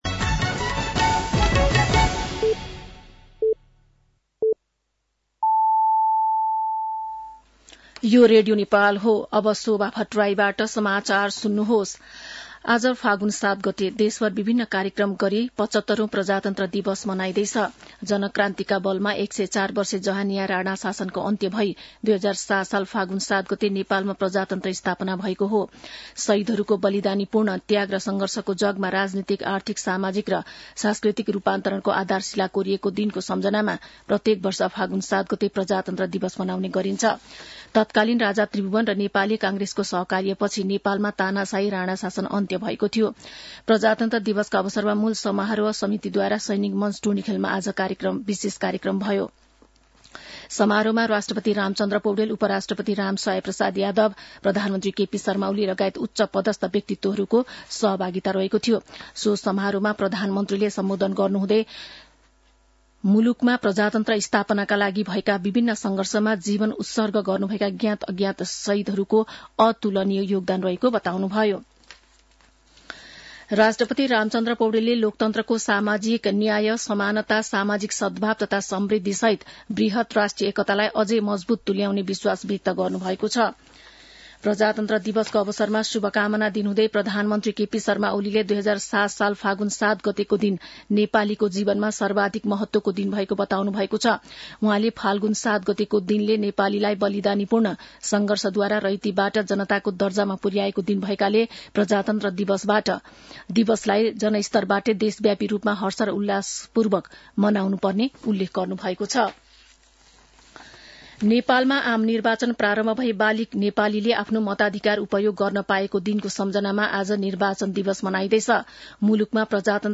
मध्यान्ह १२ बजेको नेपाली समाचार : ८ फागुन , २०८१